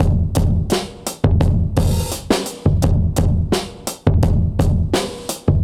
Index of /musicradar/dusty-funk-samples/Beats/85bpm/Alt Sound
DF_BeatA[dustier]_85-04.wav